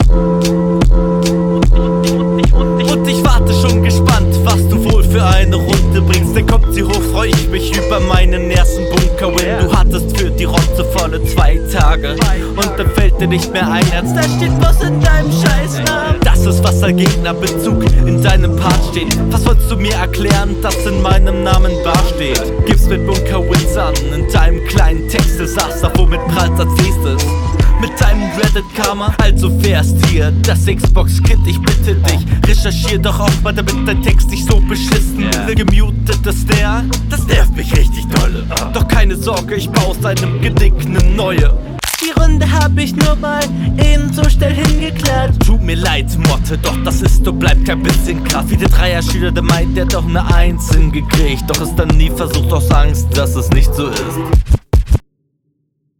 genickbruch sound genial